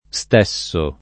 stessere [St$SSere] v.; stesso [